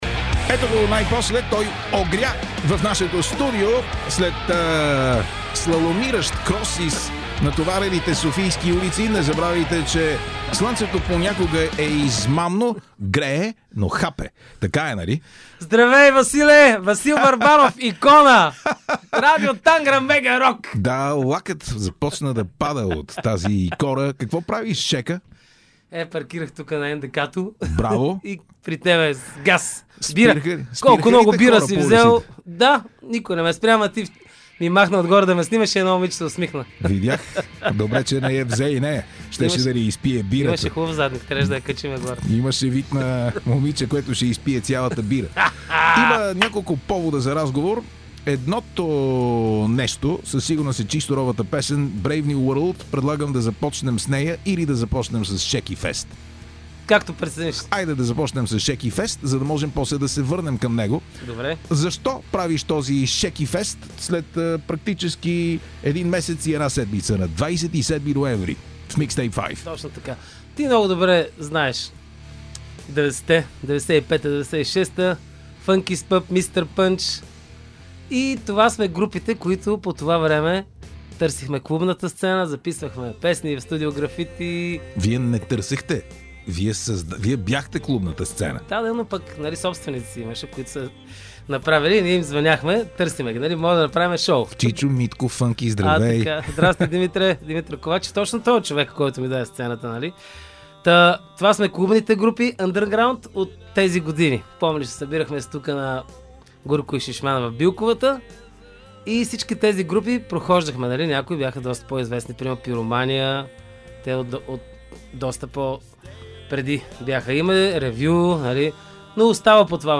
влиза с гръм, трясък и весели писъци в студиото на радио ТАНГРА МЕГА РОК!
едно интервю